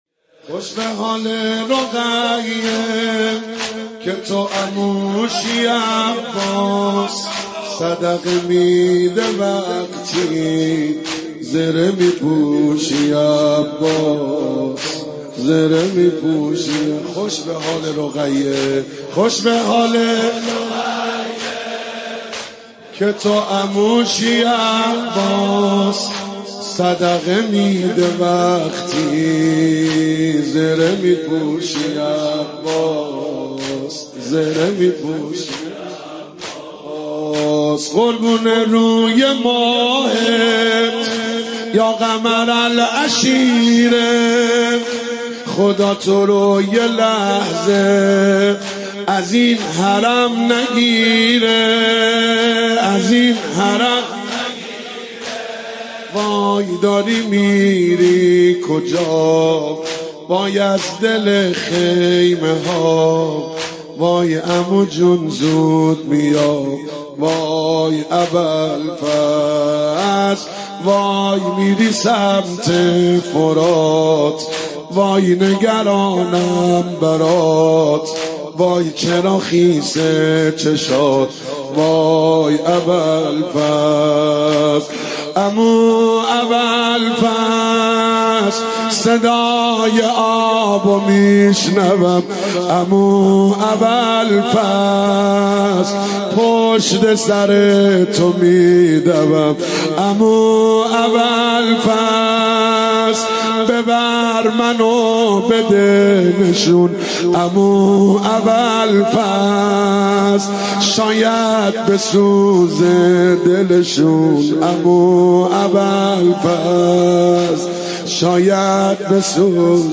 مداحی اربعین